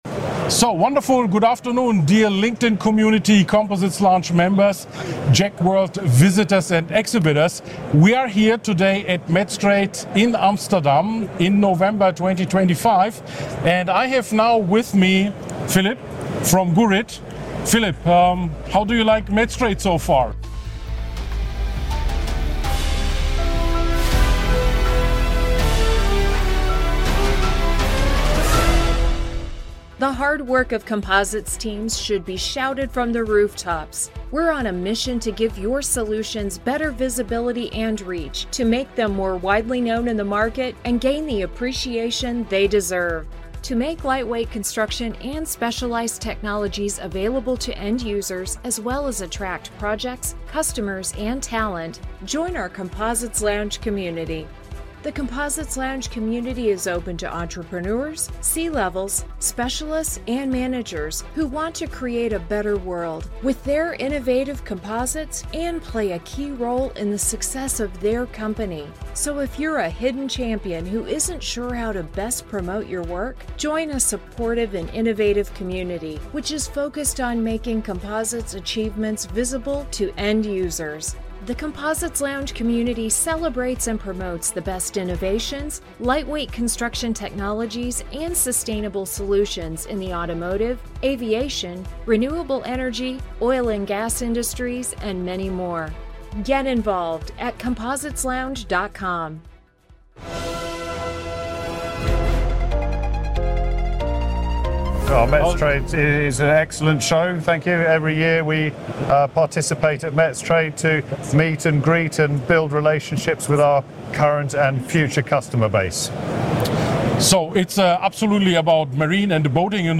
At Metstrade on RAI Amsterdam—the world’s leading B2B hub for the